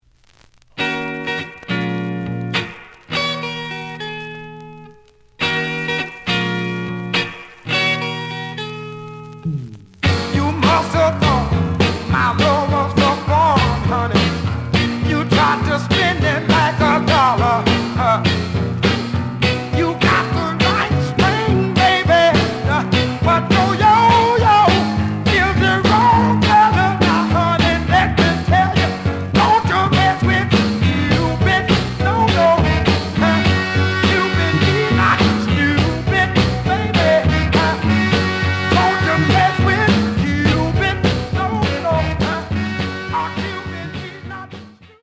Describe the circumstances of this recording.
The audio sample is recorded from the actual item. Slight noise on both sides.